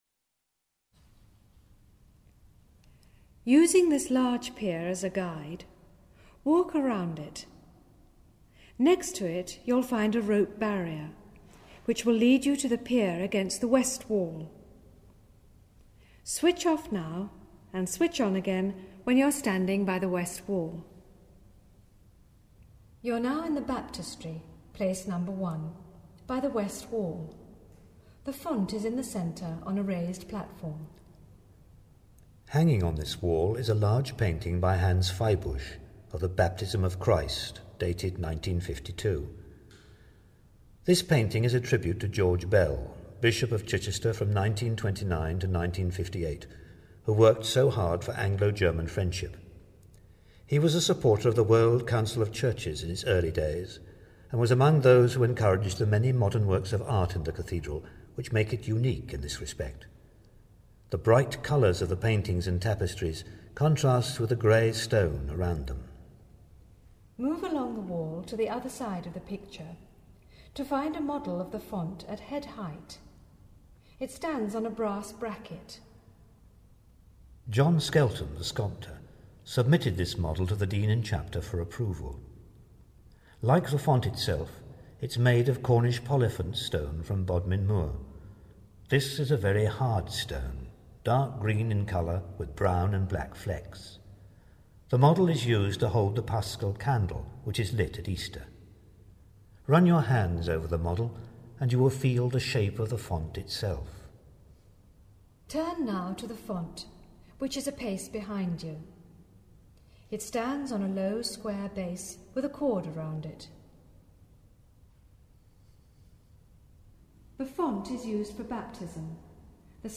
An Acoustic Fingerprint Guide of Chichester Cathedral